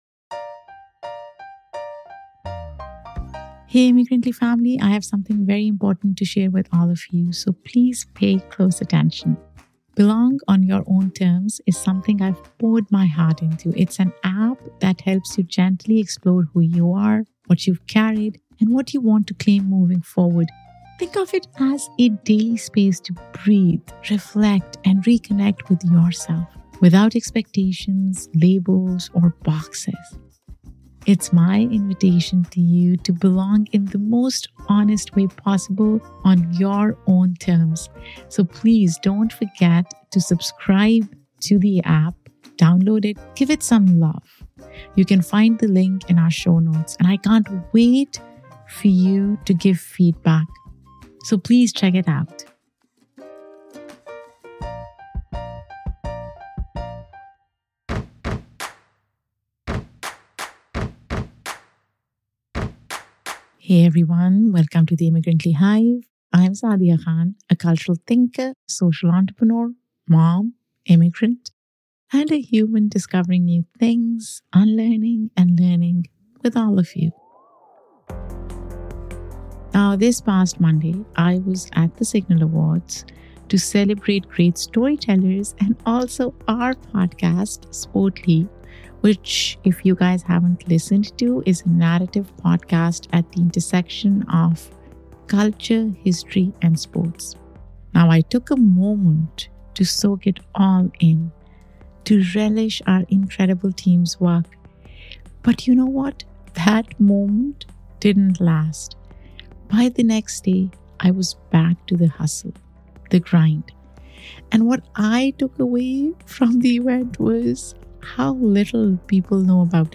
In this deeply resonant conversation